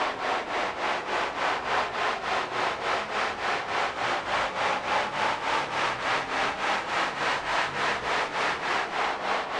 STK_MovingNoiseC-100_03.wav